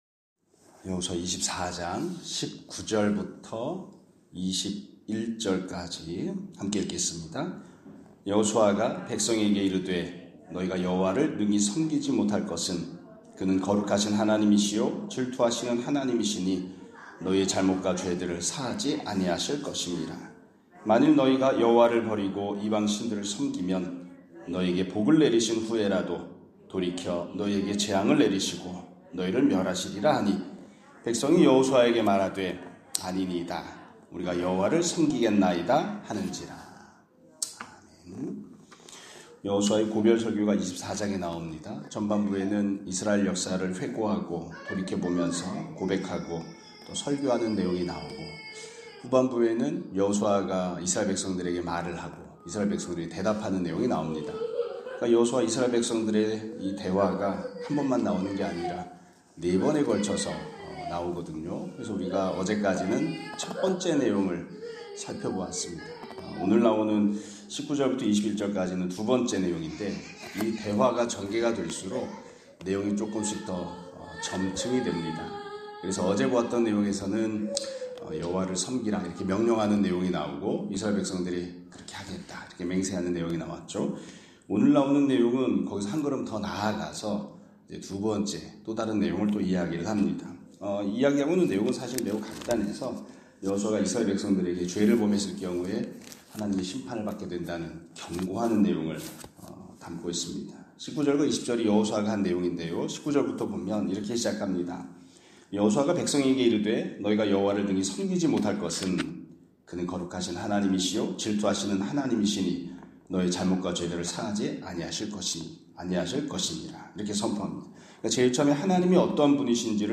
2025년 2월 28일(금요일) <아침예배> 설교입니다.